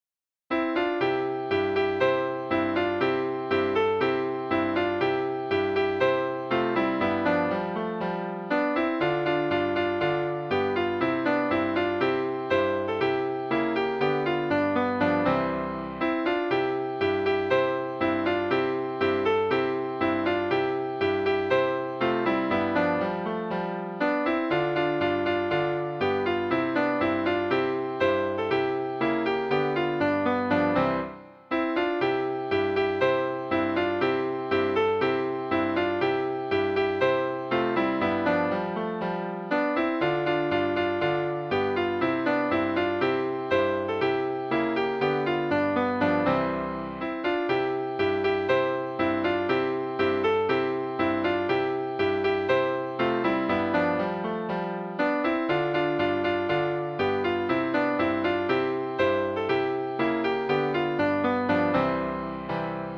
Midi File, Lyrics and Information to Billy Boy (American Version)